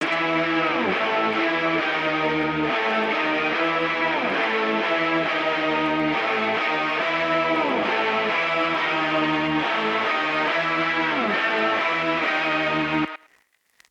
Life Of The Party Guitar (138 BPM A Minor).wav